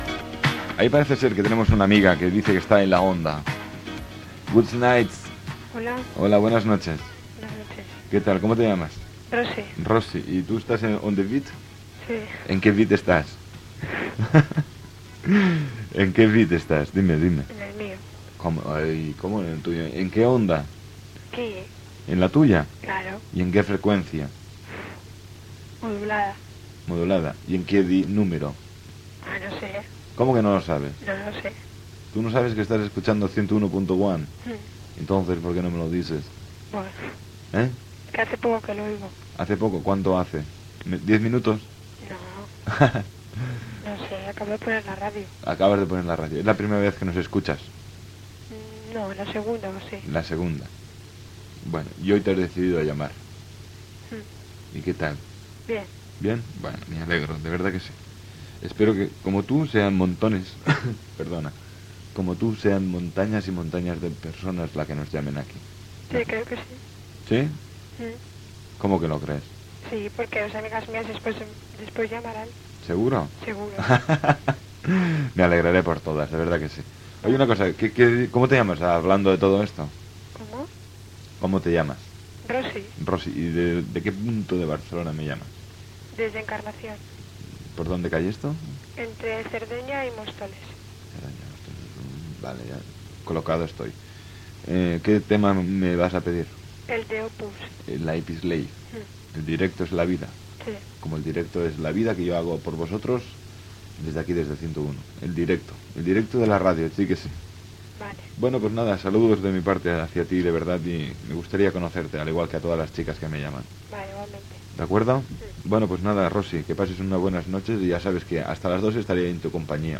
5c5b870f86fdc70a02092b99015f50151de8f77b.mp3 Títol Fono Taxi Emissora Fono Taxi Titularitat Tercer sector Tercer sector Musical Descripció Programa de trucades demanant discos.